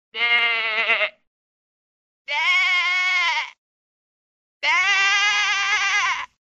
Звуки баранов, овец
Овца с ягненком